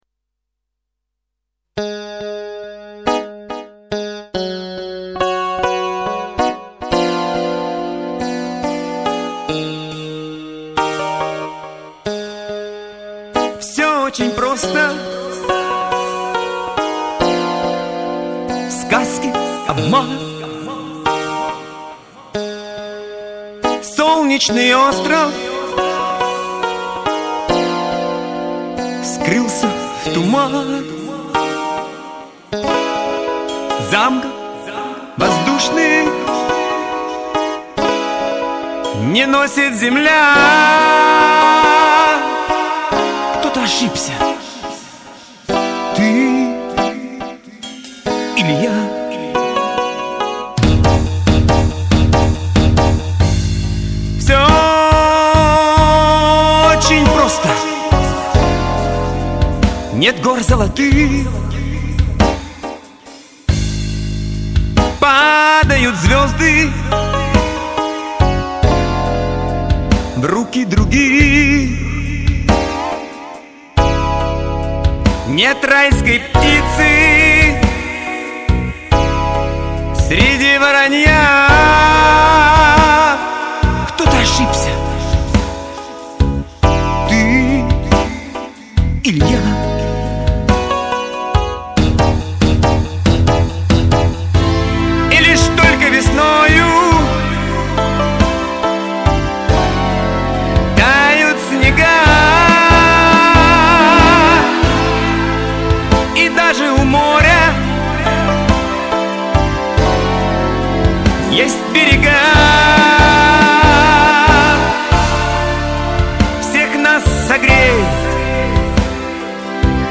Два исполнения совсем не похожи друг на друга!!!!